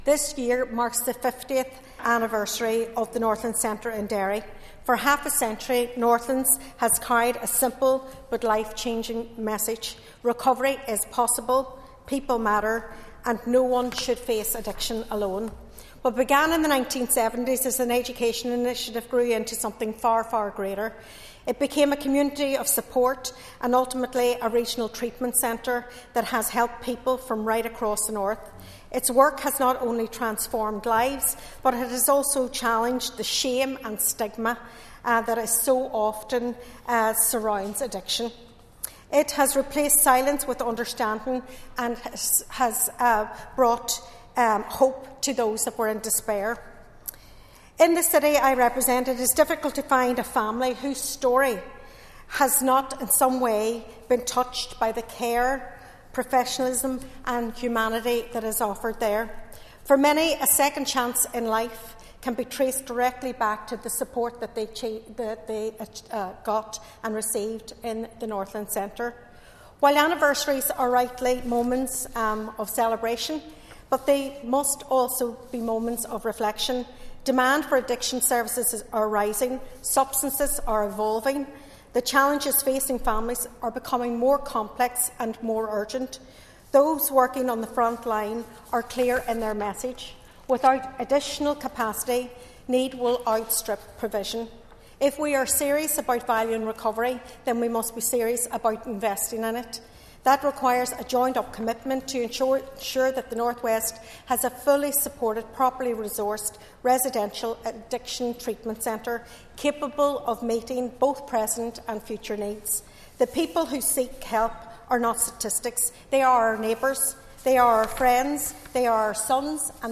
Speaking in the Assembly this week Foyle MLA, Sinead McLaughlin paid tribute to the work of the centre and called for more supports for addiction services: